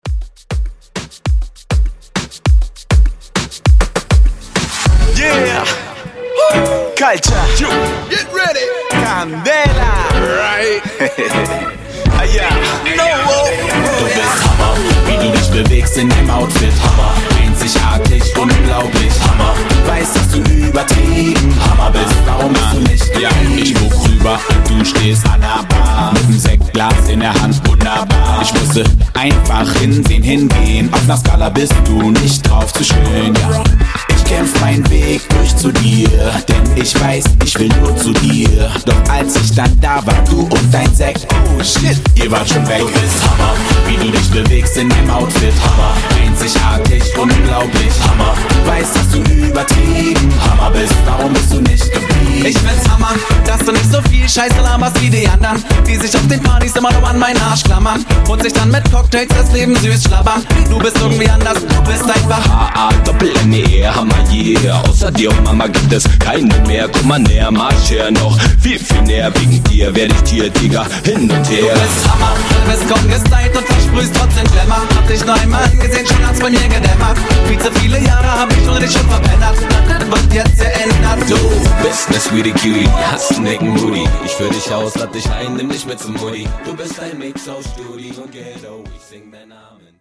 Genre: DANCE
Dirty BPM: 125 Time